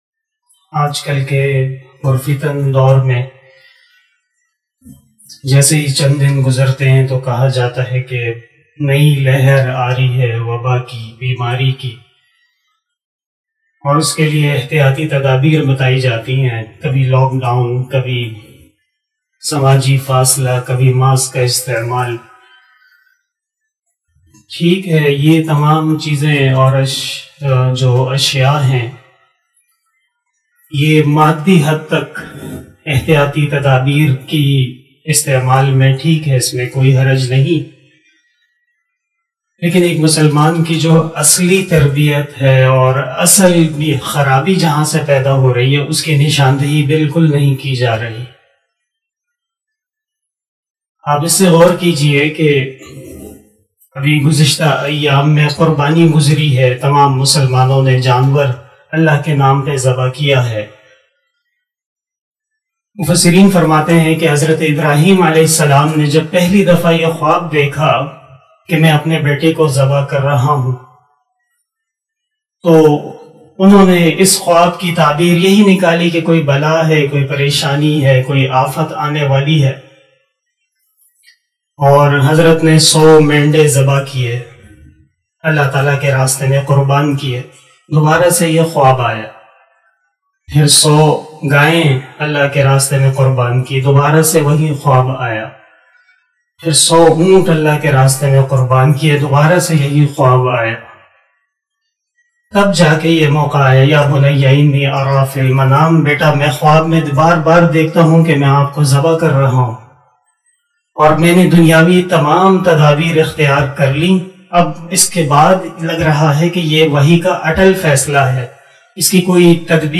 037 After asar Namaz Bayan 31 July 2021 (21 Zilhajjah 1442HJ) Saturday